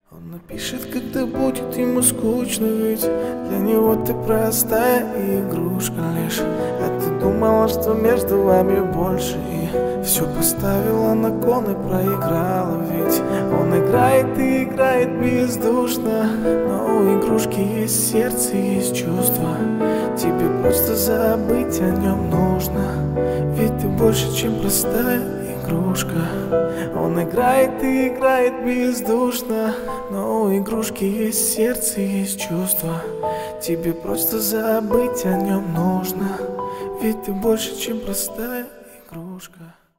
Поп Музыка
кавер # спокойные # тихие